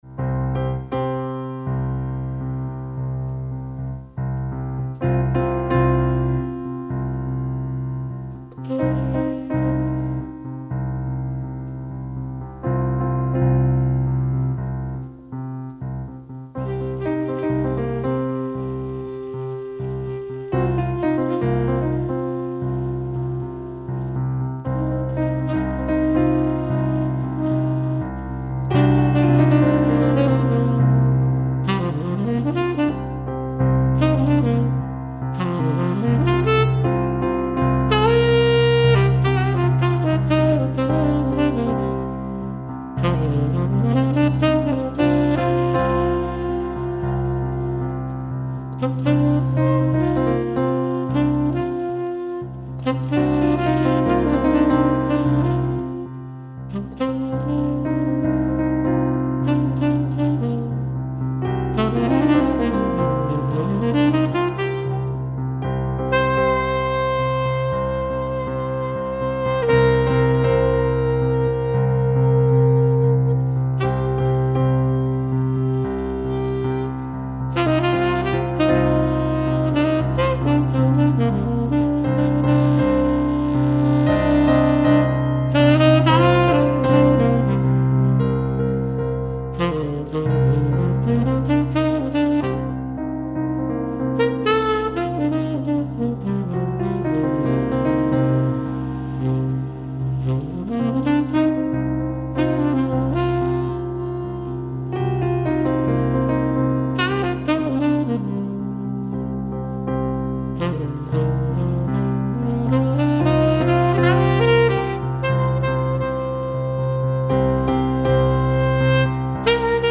Relaxing Jazz - Melodisches Saxophon mit Piano. Chillout.